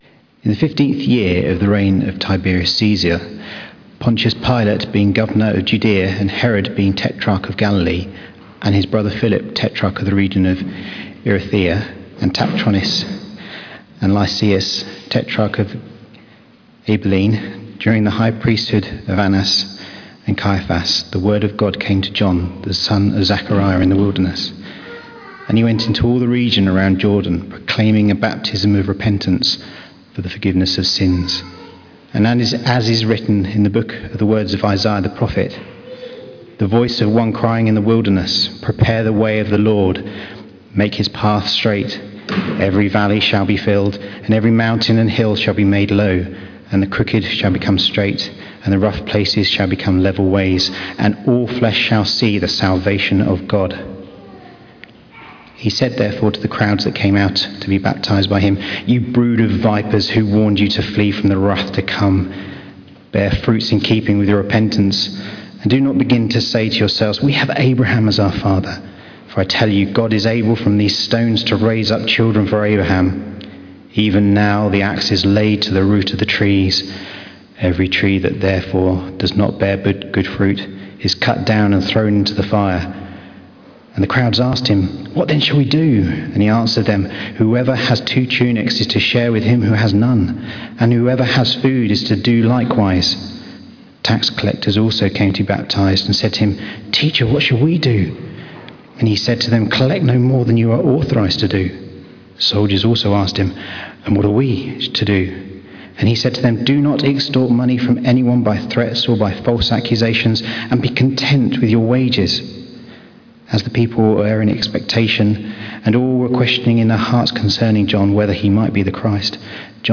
Preacher
Service Type: Sunday 11:00am